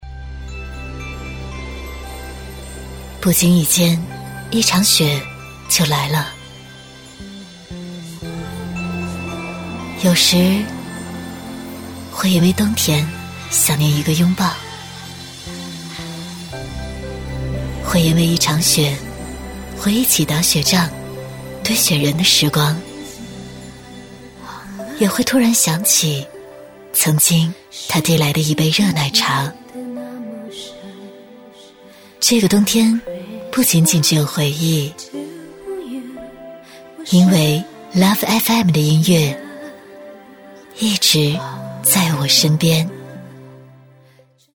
栏目包装配音【海豚配音】
女19-电台包装《爱乐调频LOVE FM》-磁性温暖
女19-电台包装《爱乐调频LOVE FM》-磁性温暖.mp3